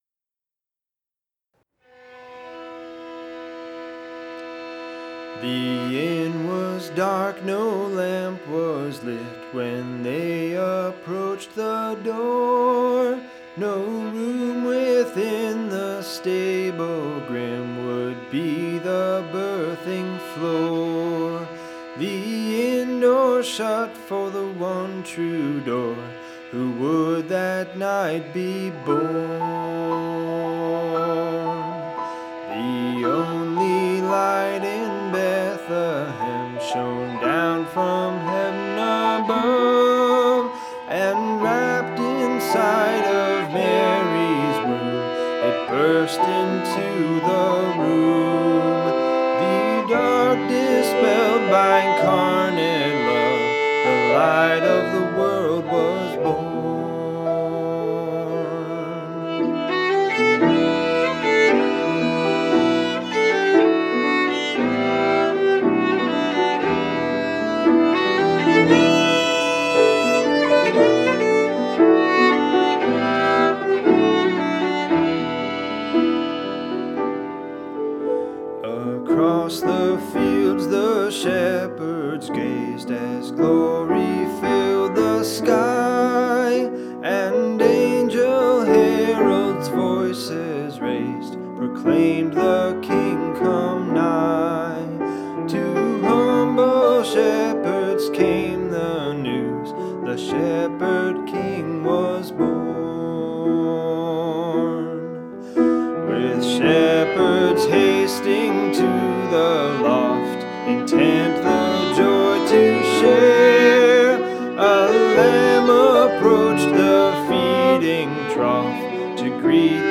A Carol for Christmas (Morning Song 8.6.8.6.8.6)
vocals
piano
violin